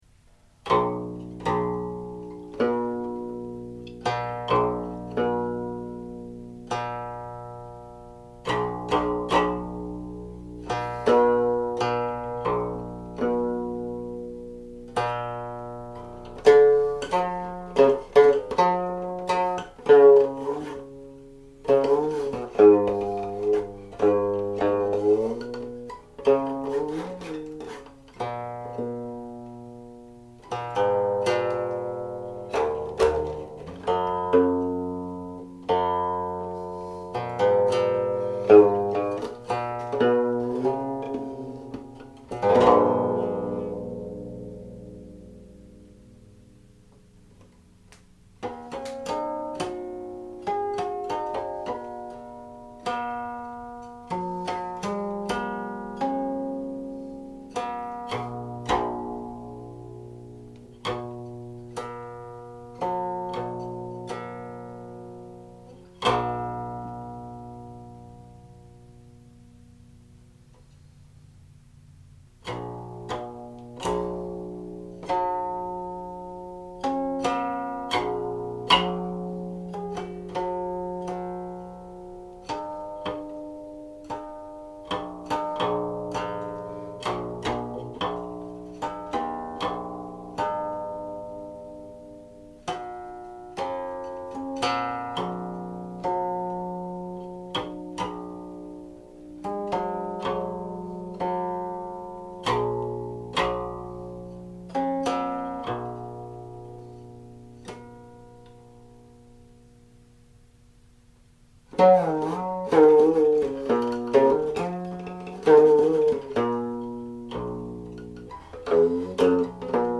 09.02   Closing harmonics
In July 2022 I made four recordings of this Guangling Zhenqu/Guangling San in my Studio for Seeking Solitude (招隱 Zhao Yin Shi) using a Roland Roland R-26 digital recorder with its internal mic.
For the first recording (which is the same as the one linked above) the bottom string of the qin is tuned between B and B flat; for the last three recordings the bottom string was tuned to A.
a qin